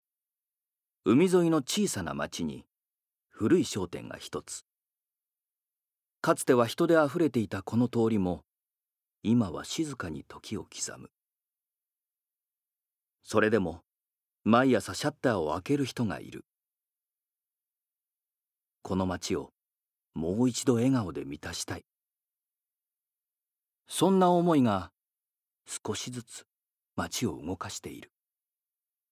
Voice Sample
ナレーション４